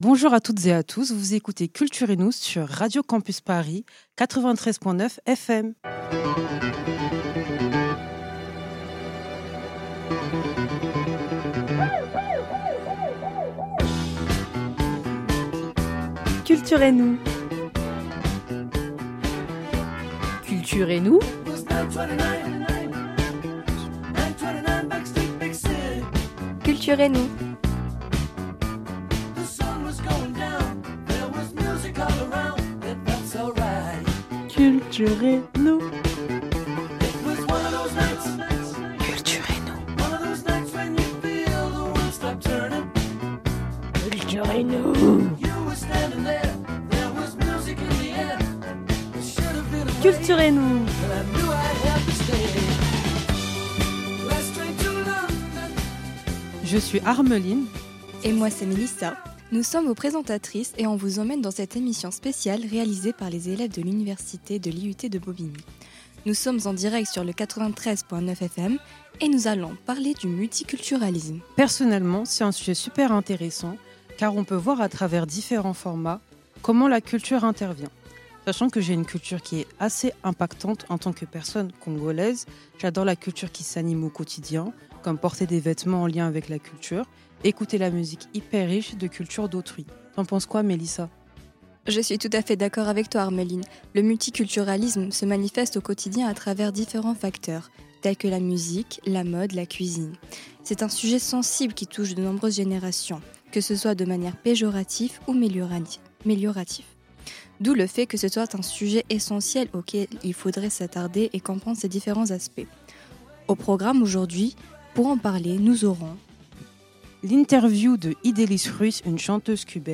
Les étudiantes de l'Université Sorbonne Paris Nord (Paris 13) ont préparé, écrit et enregistré leur émission sur la thématique du multiculturalisme.